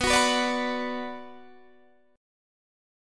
Bm Chord
Listen to Bm strummed